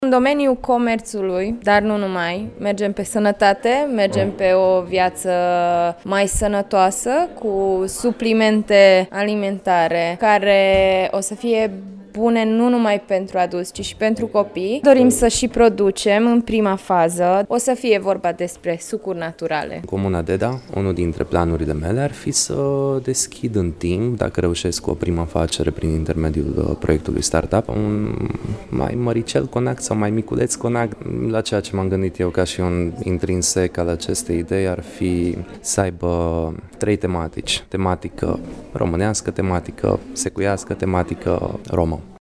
doi dintre participanți: